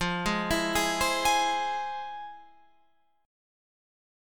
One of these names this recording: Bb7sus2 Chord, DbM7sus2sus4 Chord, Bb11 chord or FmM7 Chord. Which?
FmM7 Chord